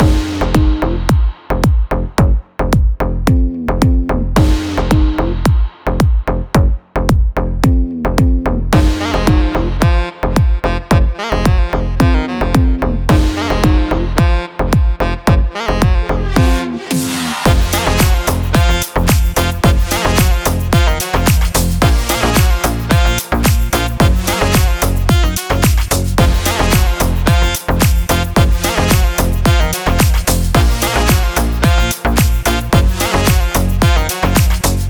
Прикрепил фоновую музыку. Серум 2, если вдруг.